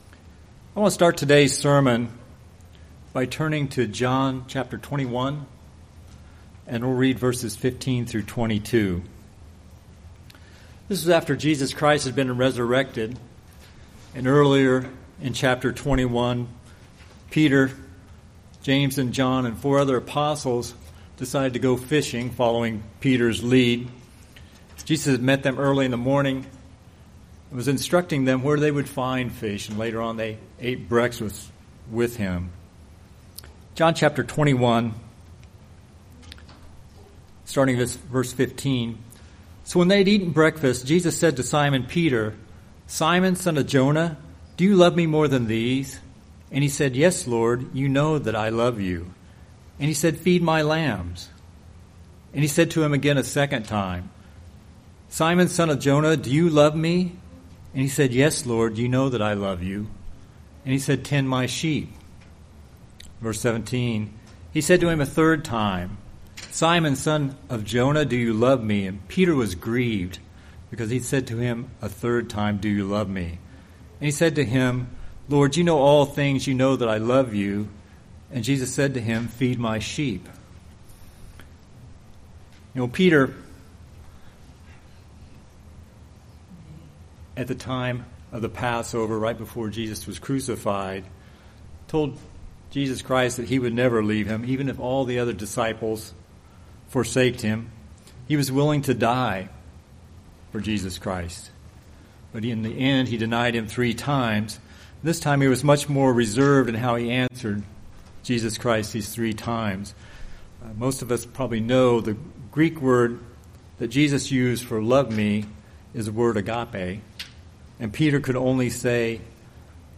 Are we willing to follow Jesus Christ in every aspect of our life, no matter what the circumstances? In this sermon we will look at what it means to actually become like Jesus Christ.